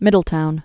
(mĭdl-toun)